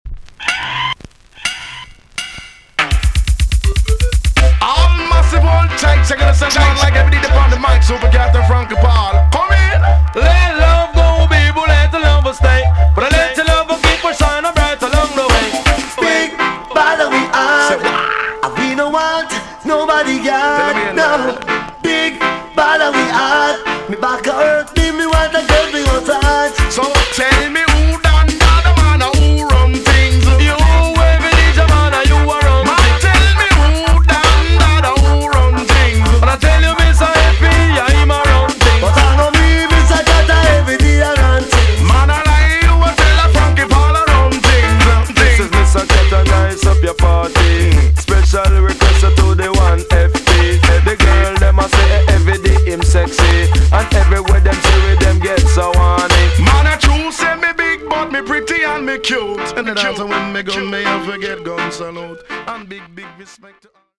TOP >80'S 90'S DANCEHALL
EX-~VG+ 少し軽いチリノイズが入りますが良好です。